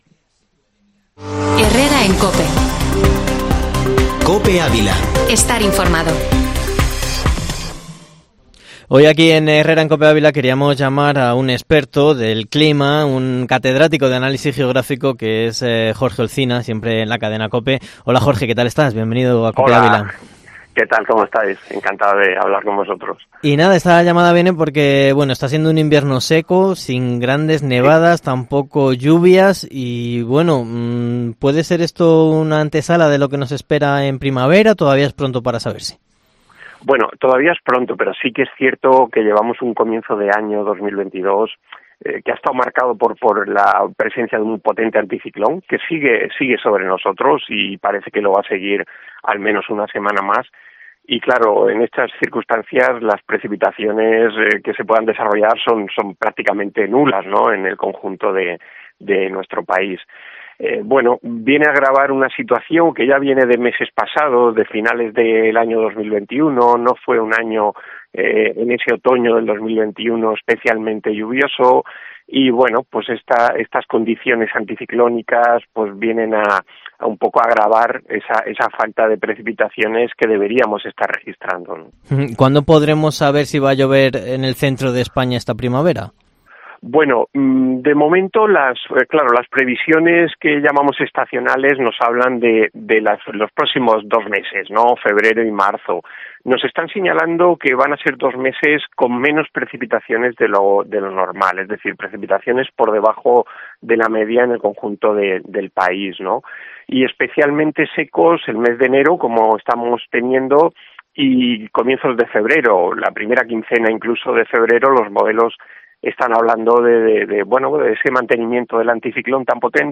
Herrera en COPE en Ávila ENTREVISTA